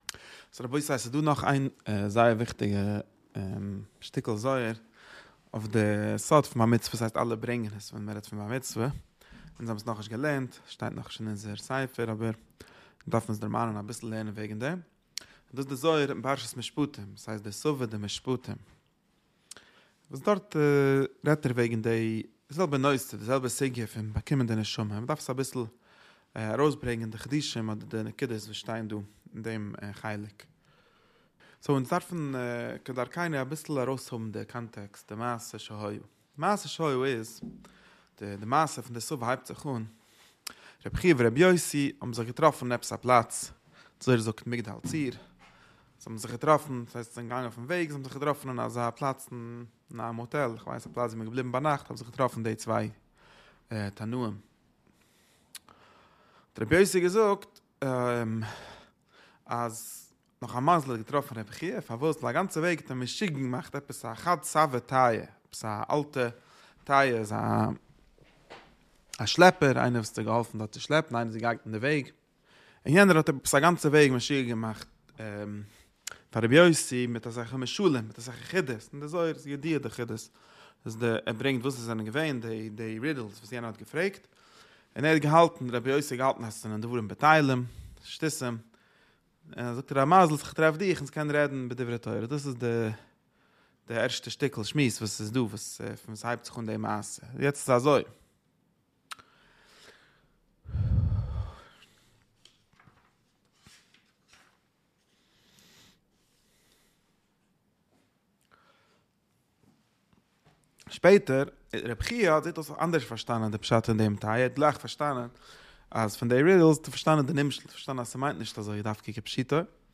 שיעור שבועי